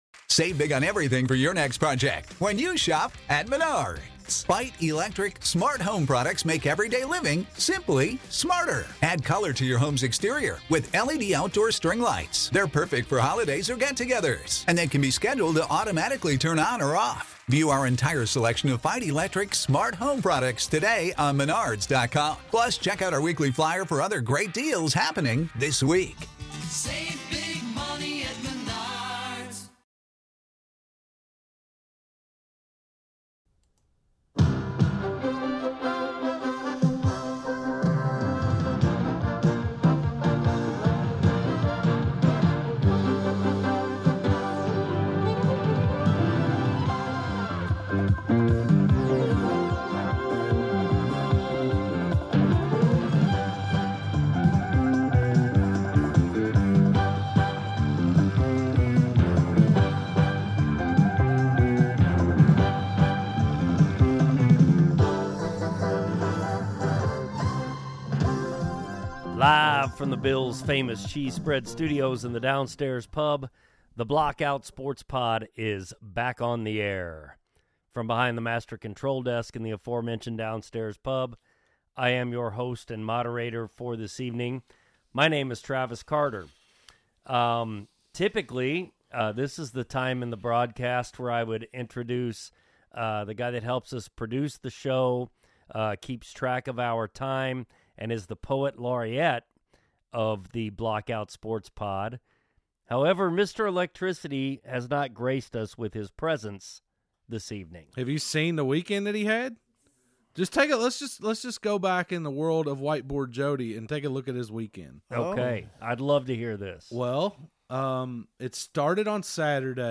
The guys are in the studio fresh off a trip to Valhalla for the PGA Championship. A recap of an eventful day, some tips for those attending and a look at the contenders in the second major of the year.